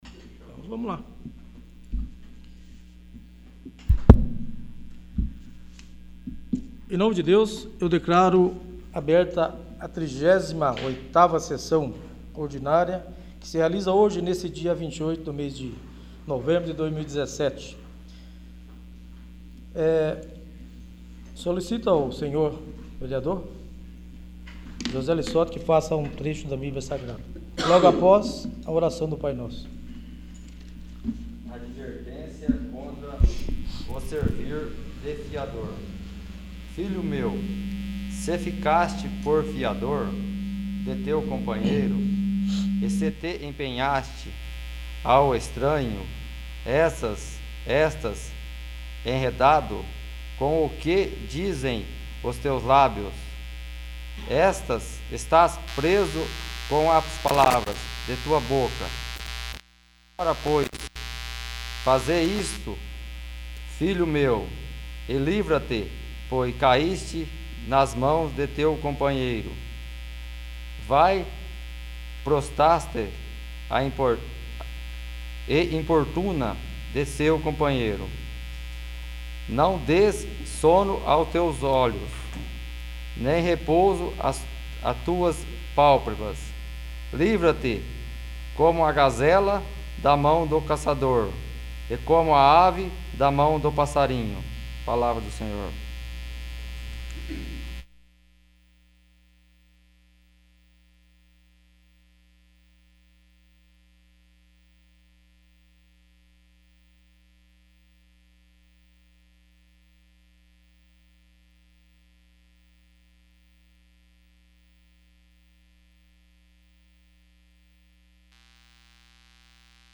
38º. Sessão Ordinária